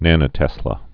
(nănə-tĕslə)